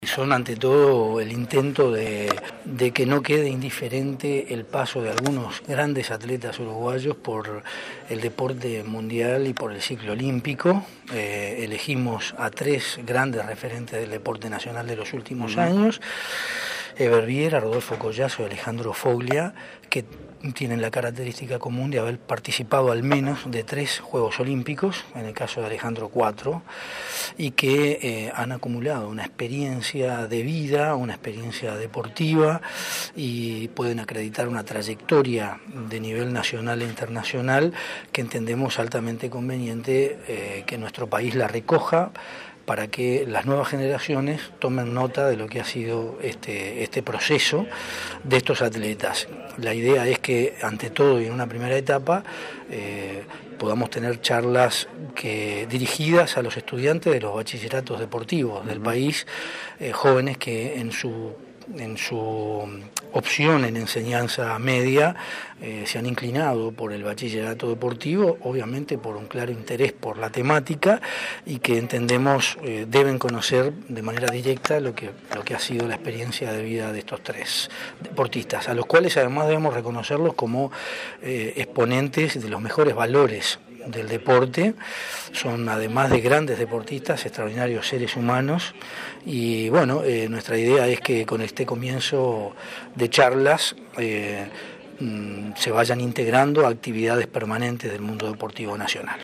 Las charlas buscan motivar a los alumnos, afirmó el secretario del Deporte, Fernando Cáceres. Esta experiencia se realiza tras un acuerdo entre la Secretaría, UTU y el Comité Olímpico.